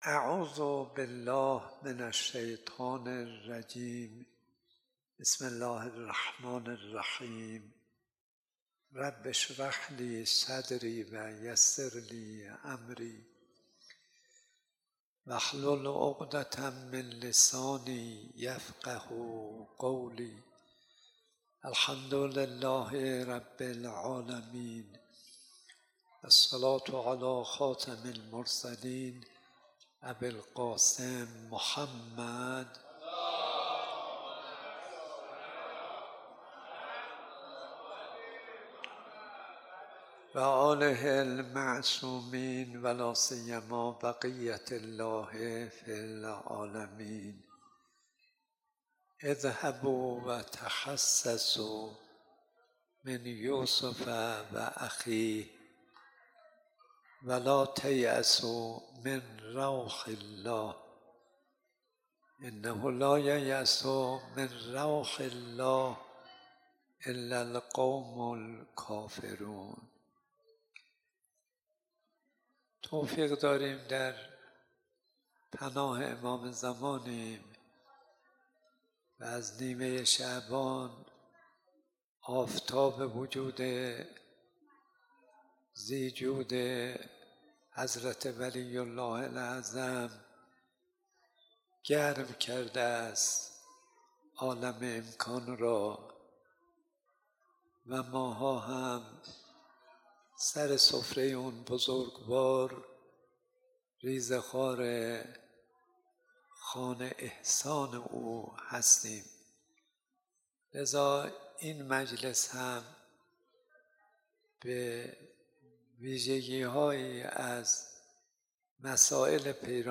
مسجد ازگل حریم ملکوت ۲۱۰ درس اخلاق آیت الله صدیقی؛ ۰۳ اردیبهشت ۱۴۰۳ در حال لود شدن فایل های صوتی...